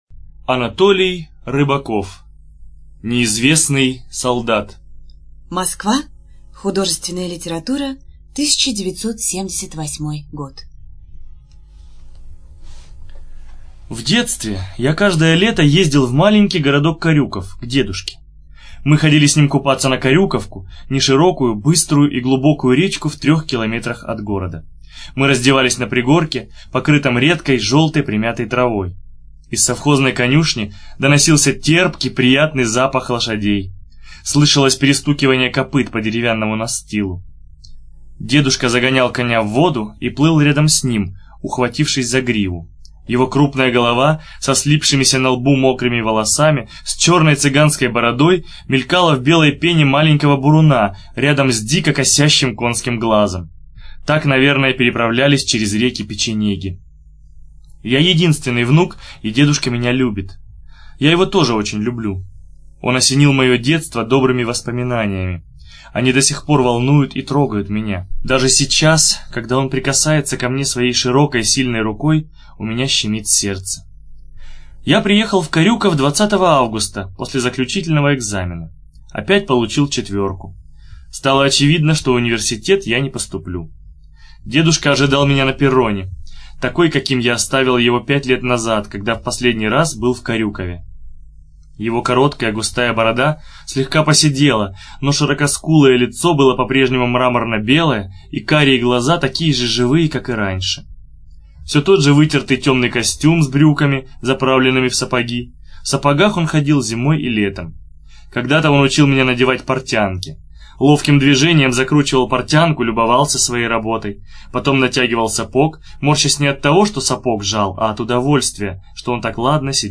ЖанрДетская литература